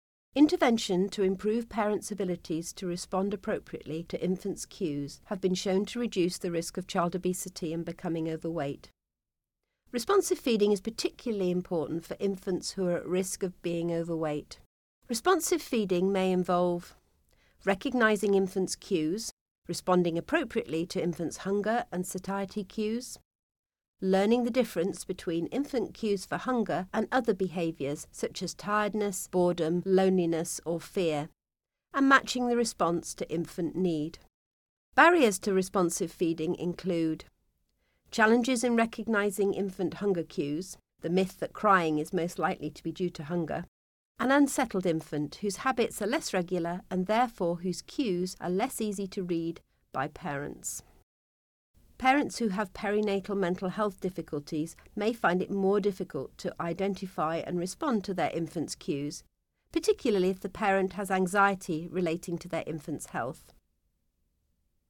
Narration audio (MP3) Narration audio (OGG)